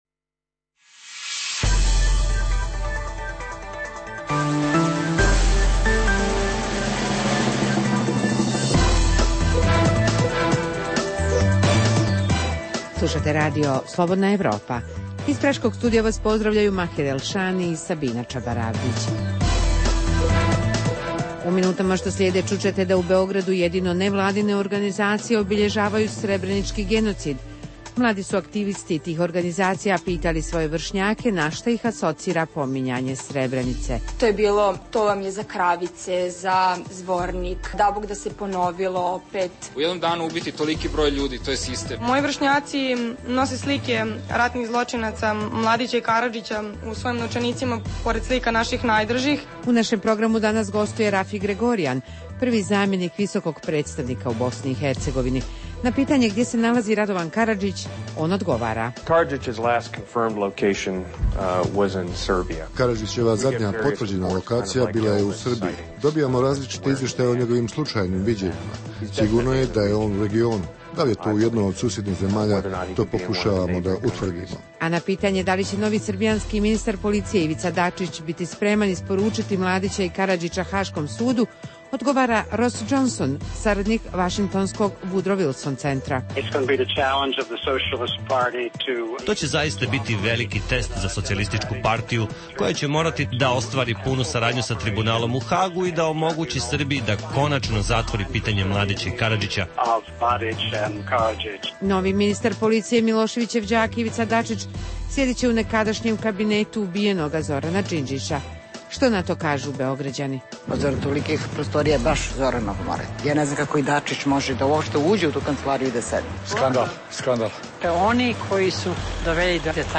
dva intervjua